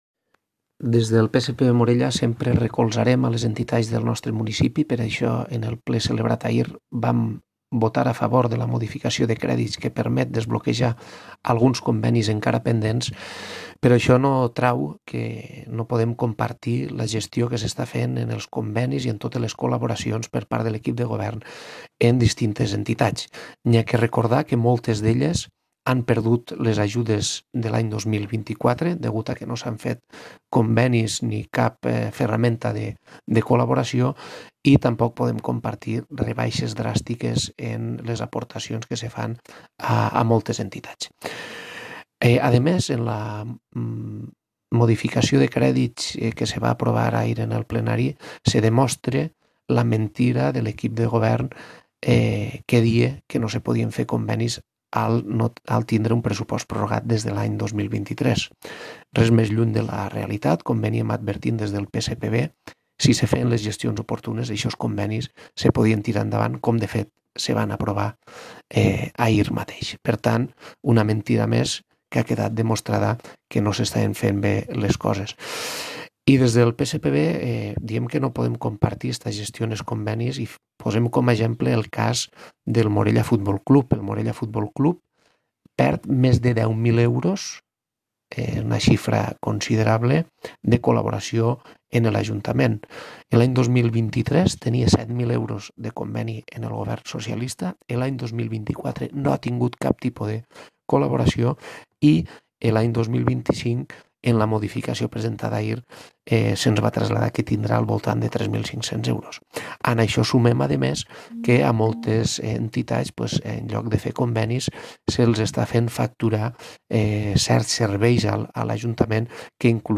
DECLARACIONS-RHAMSES-RIPOLLES-CONVENIS-MORELLA-online-audio-converter.com_.mp3